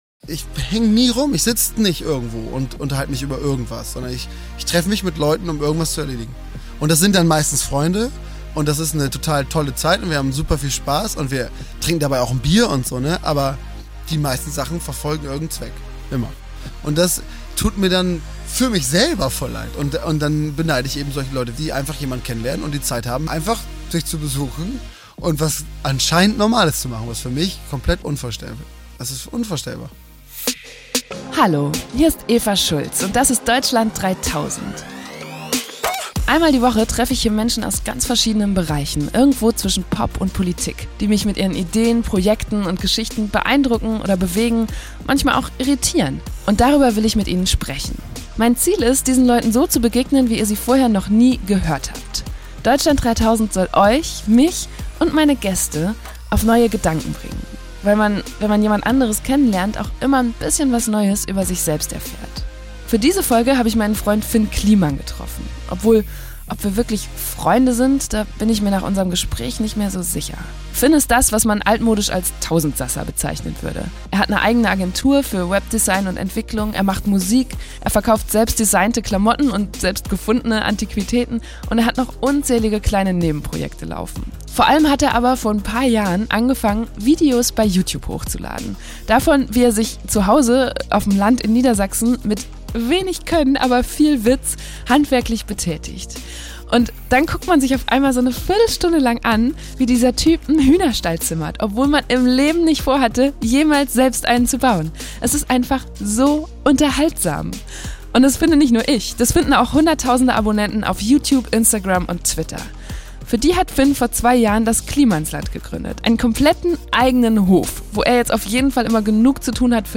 Ich habe ihn im Kliemannsland, seinem Hof in einem Dorf in Niedersachsen, besucht, und ‘ne gute Stunde lang mit ihm gequ...